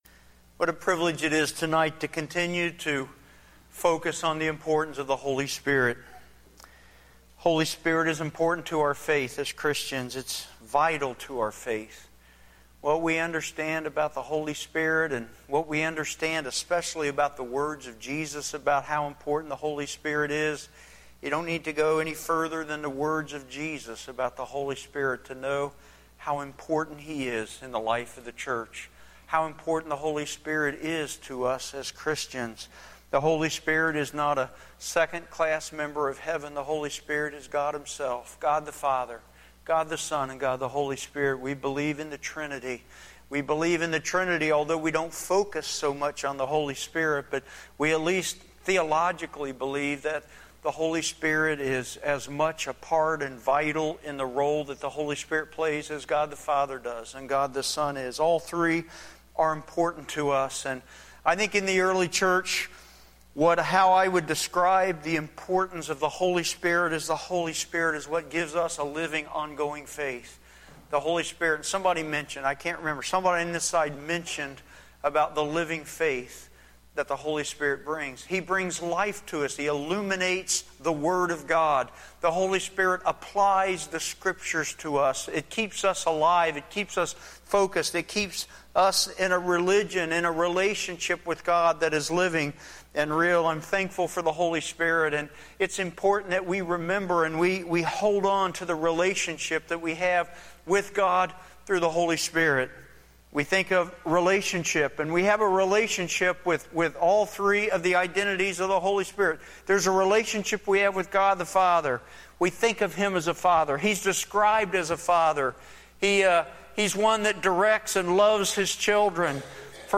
6-8-14-pm-Sermon.mp3